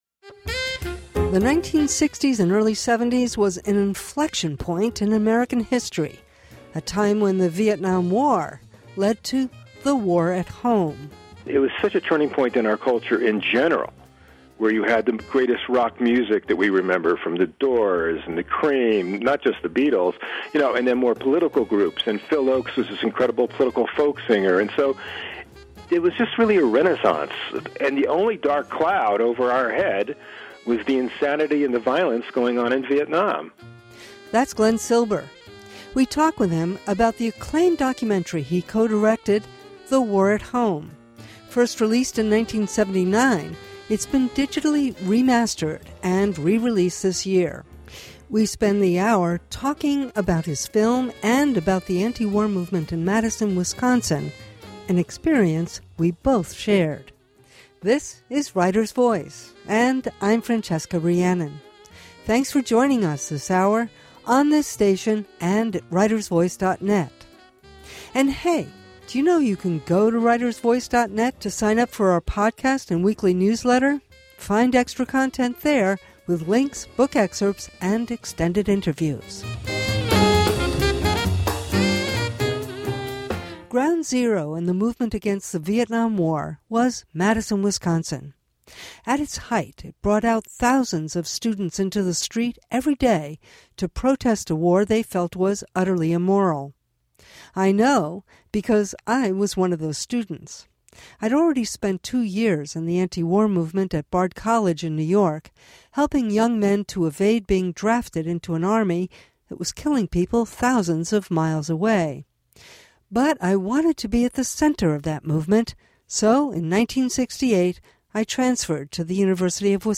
Writer’s Voice — in depth conversation with writers of all genres.